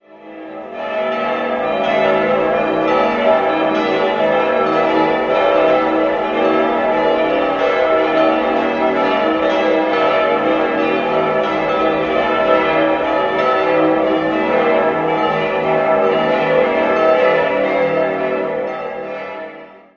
Glocke 1 e'-6 1.046 kg 1.216 mm 1965 Rincker, Sinn Glocke 2 gis'-6 580 kg 970 mm 1931 F. Schilling&Söhne, Apolda Glocke 3 a'-6 455 kg 912 mm 1965 Rincker, Sinn Glocke 4 h'-6 300 kg 805 mm 1965 Rincker, Sinn Glocke 5 d''-4 230 kg 733 mm 1965 Rincker, Sinn Glocke 6 e''-4 155 kg 647 mm 1965 Rincker, Sinn Quelle